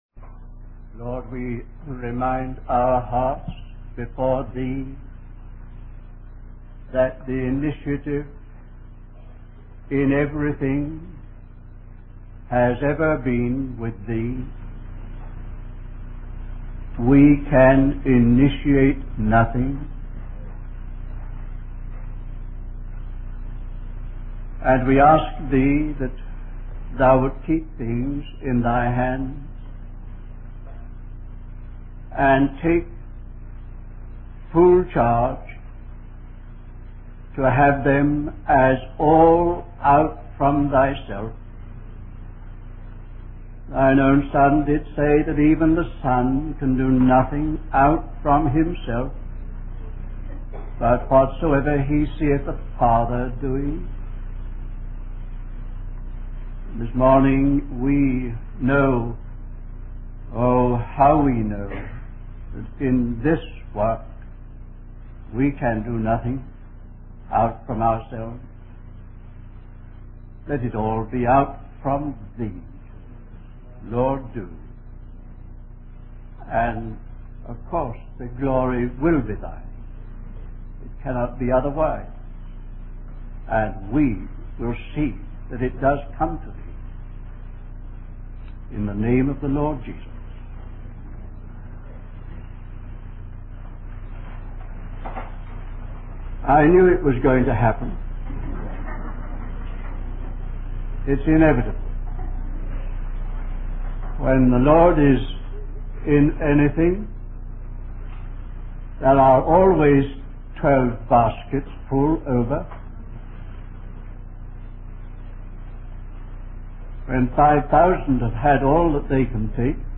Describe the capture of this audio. Wabanna (Atlantic States Christian Convocation)